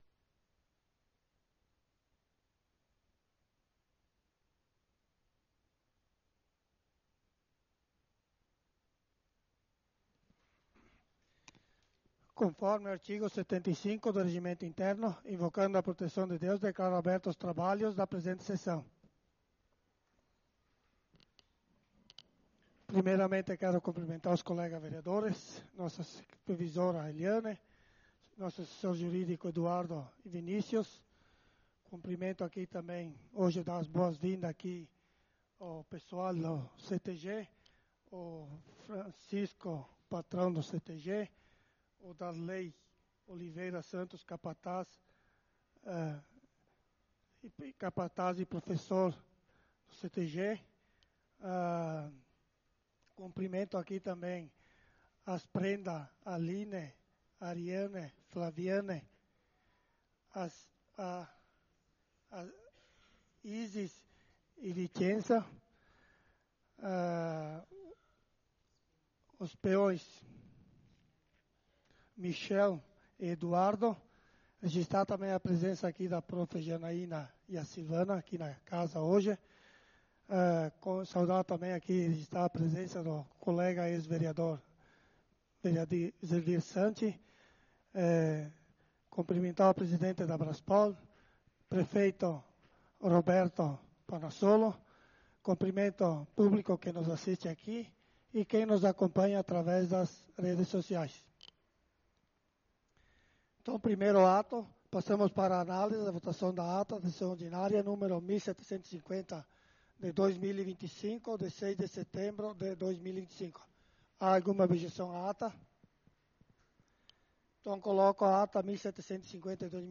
Sessão Ordinária do dia 10/09/2025 - Câmara de Vereadores de Nova Roma do Sul